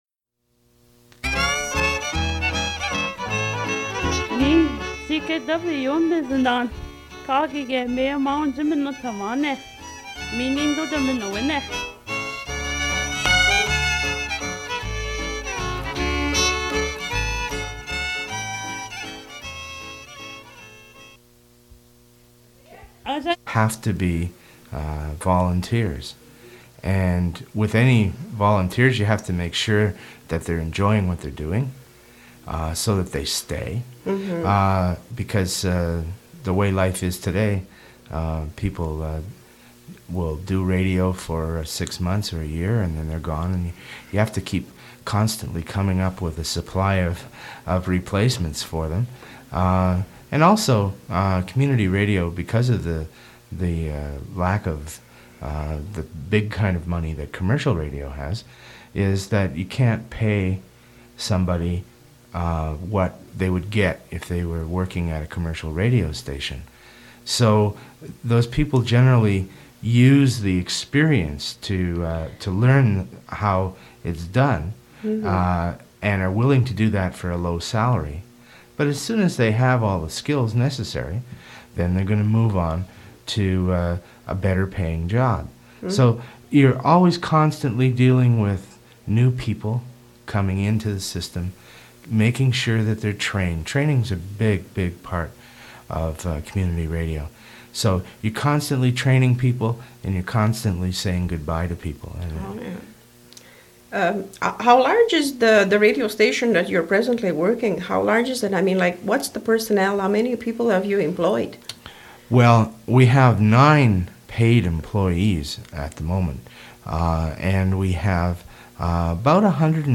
Fait partie de Interview with the manager of the station radio